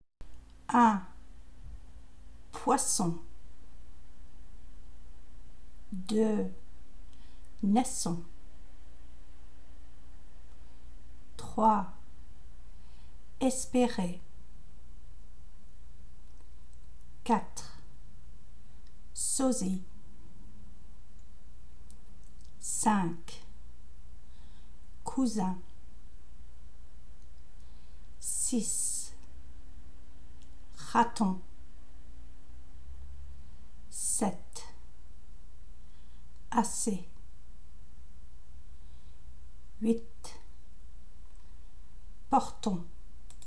Pronunciation – /s/ vs /z/